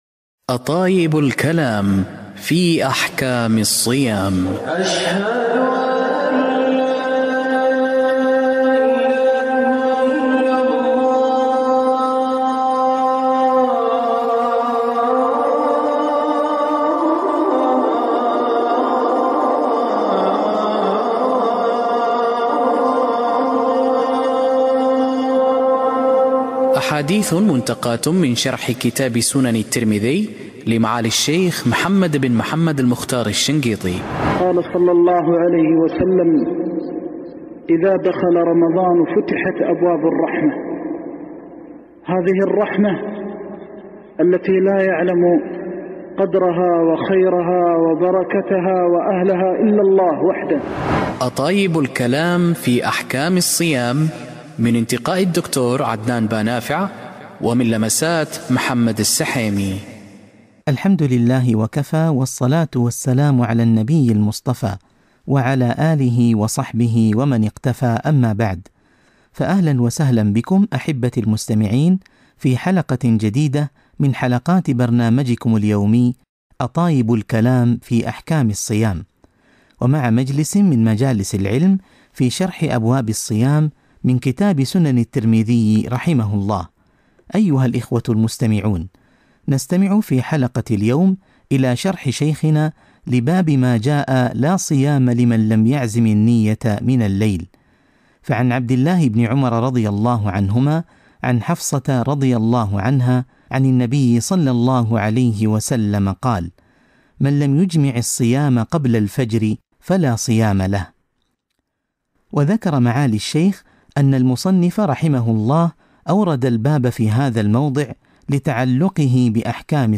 دروس برنامج أطايب الكلام في أحكام الصيام-درس (9)